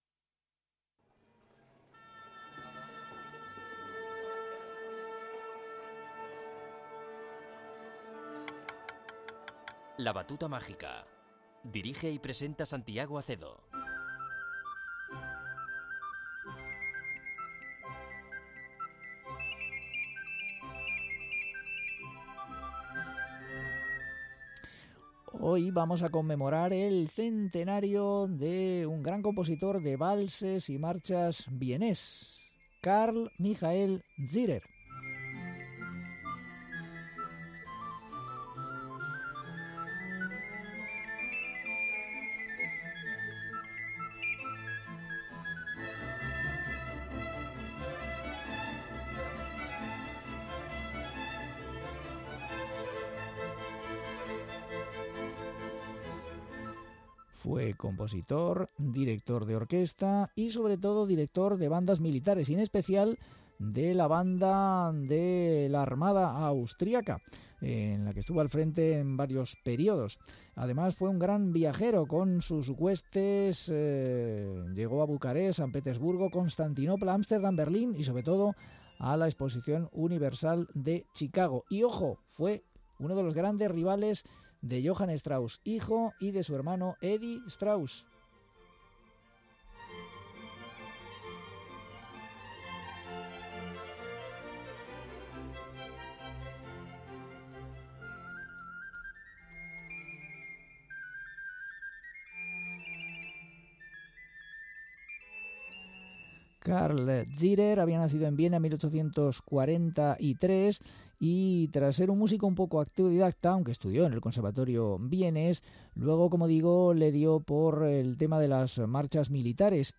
polcas
valses